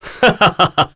Roman-Vox-Laugh.wav